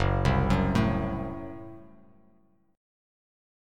F#dim Chord
Listen to F#dim strummed